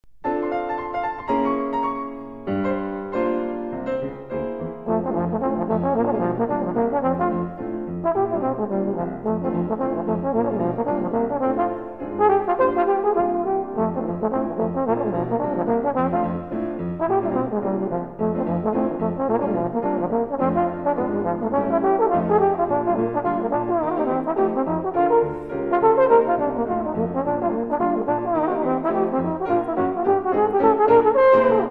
pure fun in a two-beat swing!